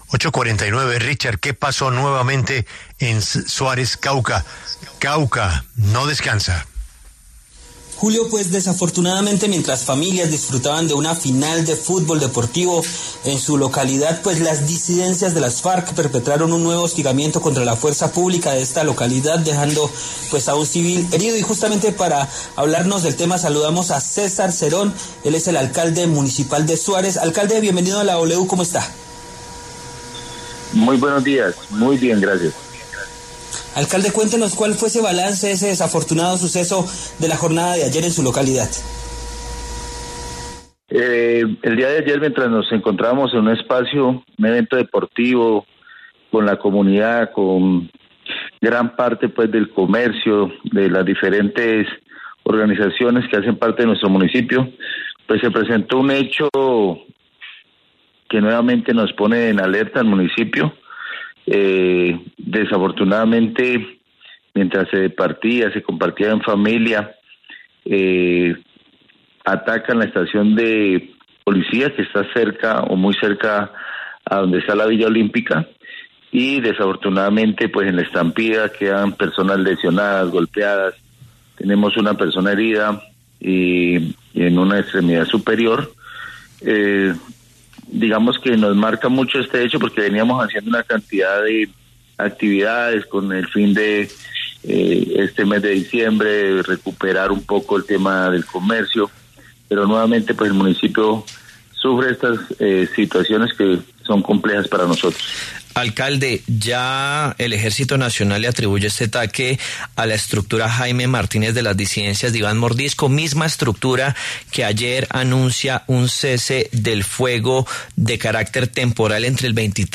El alcalde de Suárez, César Cerón, en entrevista con La W aseveró que, la persistencia de la violencia ha desbordado la capacidad de respuesta del municipio.